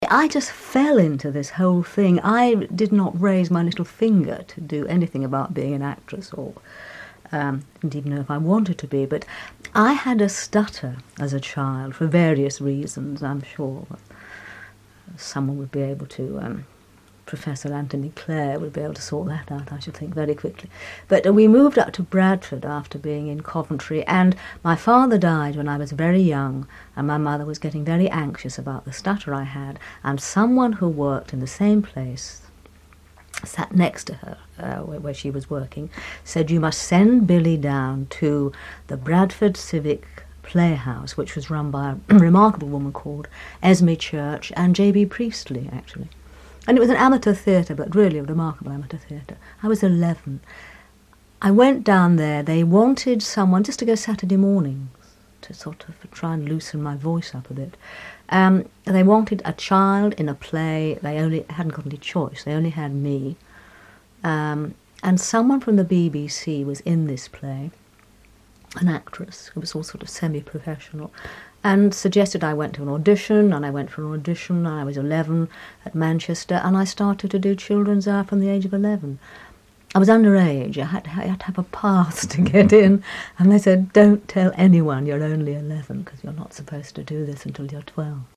Billie Whitelaw in conversation with Terry Waite for BBC Radio Suffolk, in 1998.